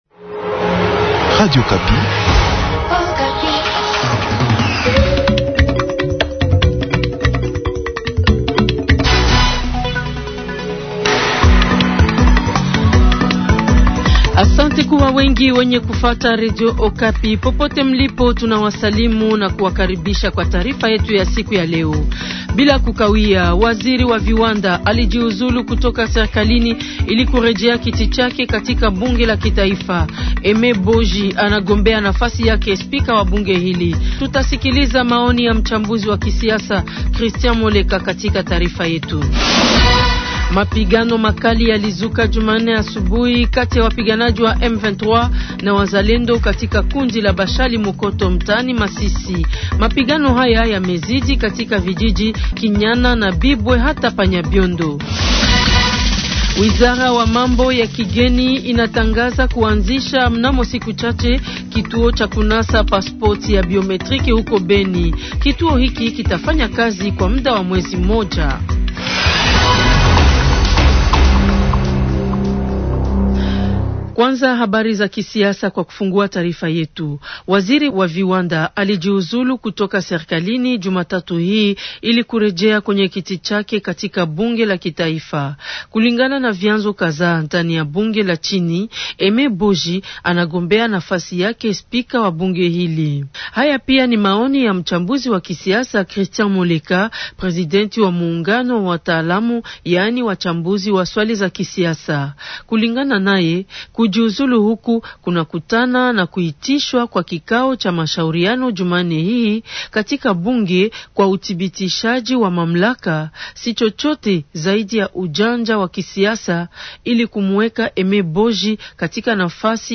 Journal Swahili SOIR du 21 octobre 2025